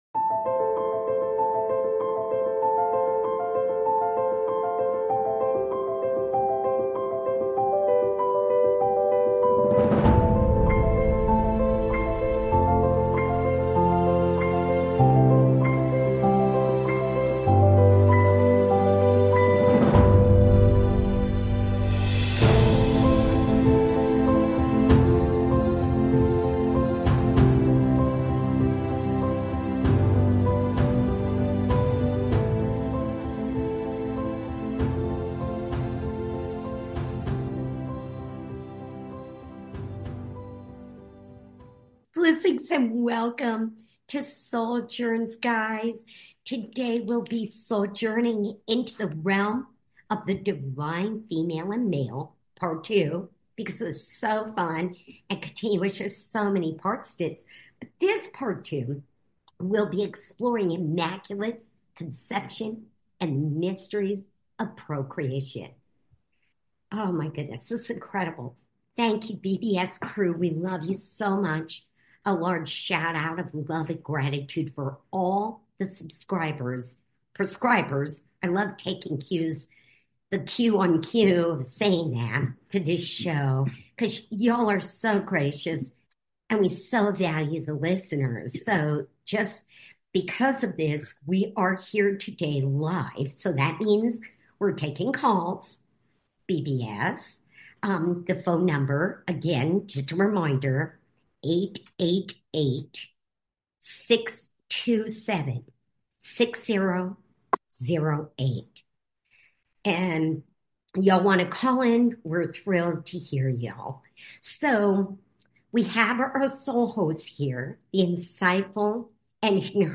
Talk Show Episode, Audio Podcast, SOULJOURNS and Divine Feminine and Masculine Energies, Part 2 on , show guests , about divine feminine,the masculine,Immaculate Conception,Procreation, categorized as Health & Lifestyle,Love & Relationships,Philosophy,Emotional Health and Freedom,Self Help,Sexuality,Society and Culture,Spiritual,Astrology